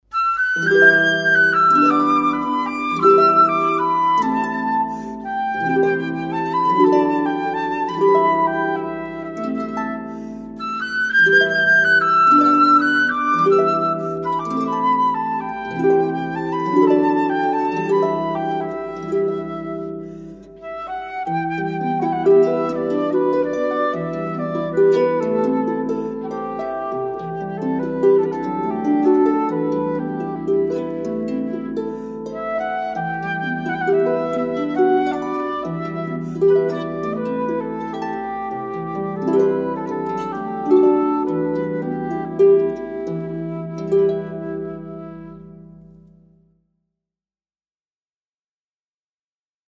• A Flauta Transversal participa nos momentos instrumentais, juntamente com a Harpa ou o Órgão.
Instrumental Harpa Flauta
18-instrumental_harpa_flauta.mp3